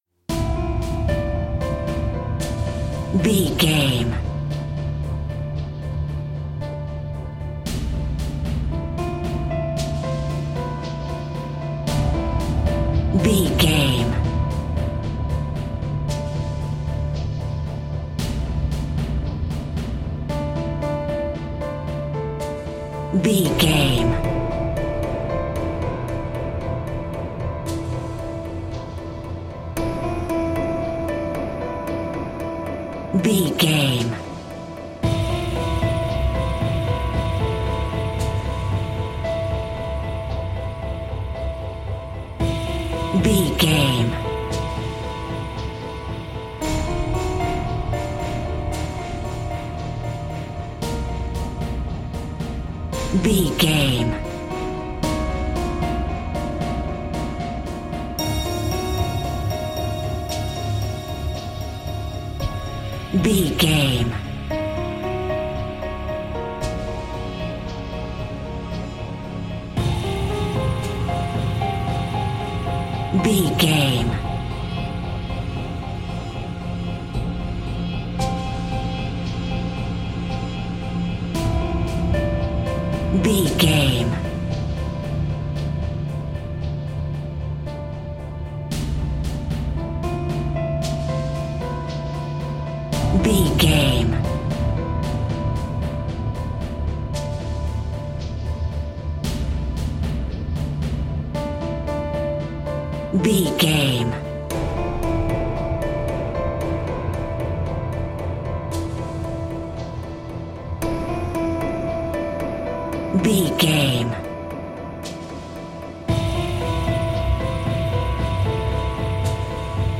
Thriller
Aeolian/Minor
strings
drum macine
synthesiser
piano
ominous
dark
suspense
haunting
creepy